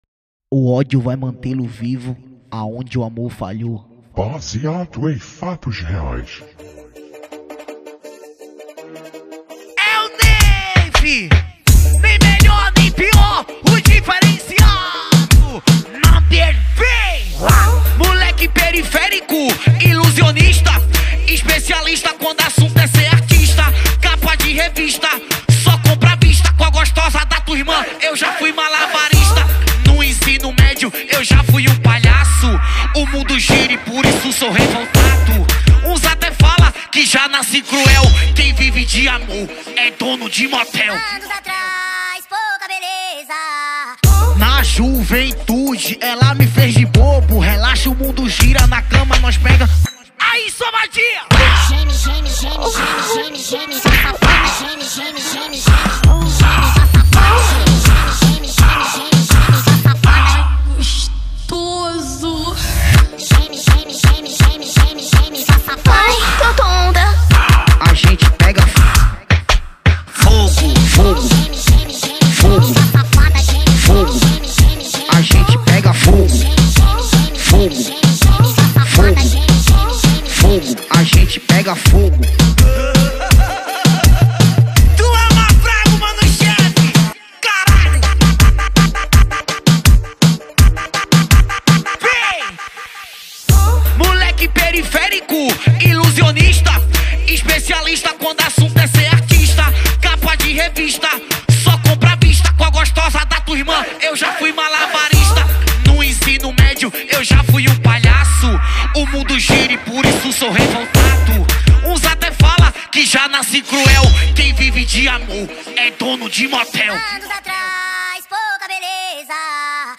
2024-07-04 20:27:03 Gênero: Funk Views